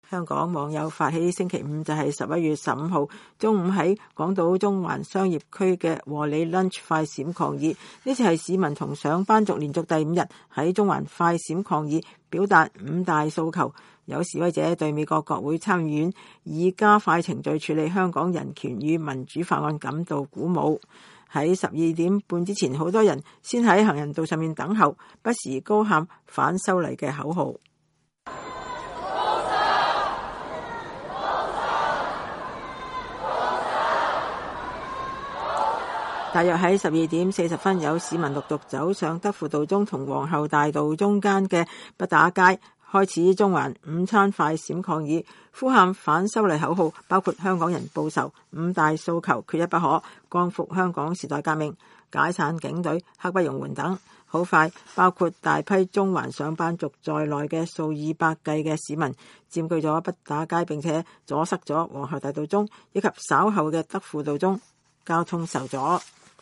12點半前，許多人先在行人道上等候，不時高喊反修例口號。